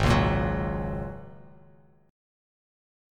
G#7sus2sus4 chord